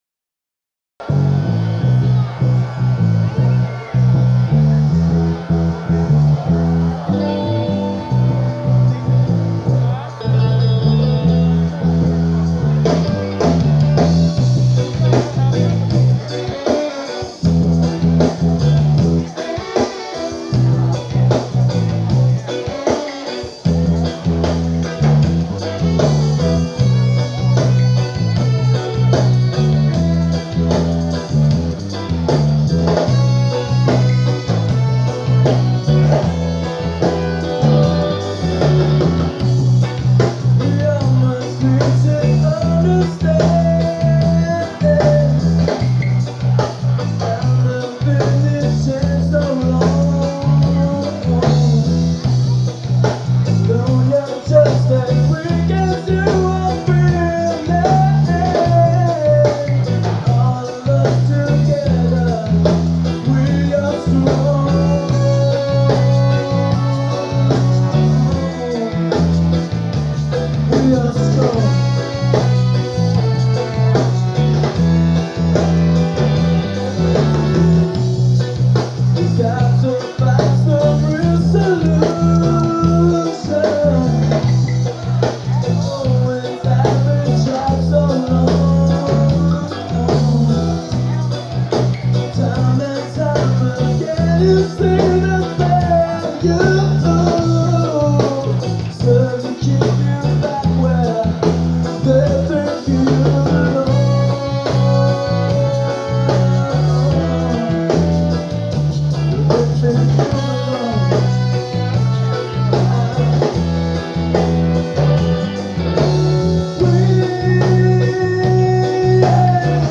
Live Tapes